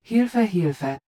ggl_hu-HU-Wavenet-A_-2.wav